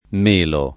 m%lo] s. m. («albero») — es. con acc. scr.: Ed i pèschi ed i méli tutti eran fior bianchi e vermigli [